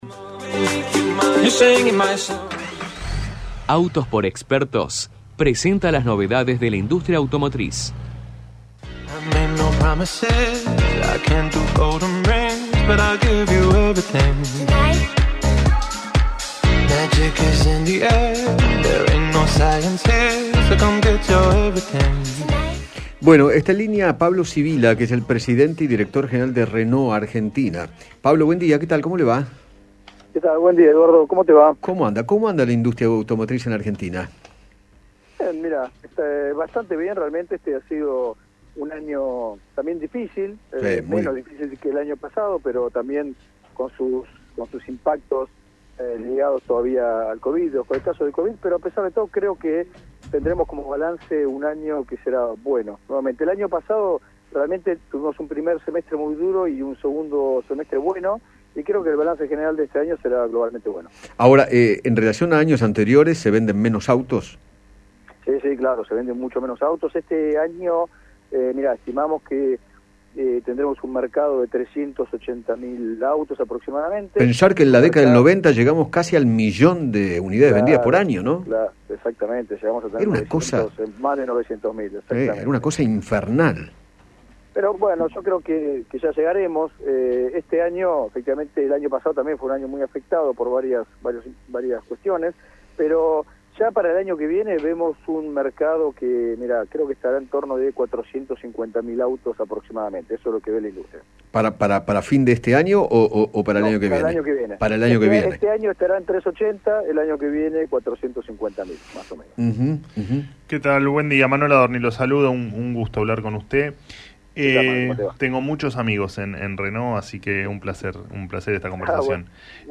conversó con Eduardo Feinmann sobre el presente de la industria automotriz y aseguró que “está bastante bien aunque fue un año difícil”.